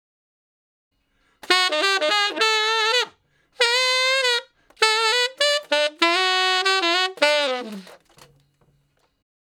066 Ten Sax Straight (D) 17.wav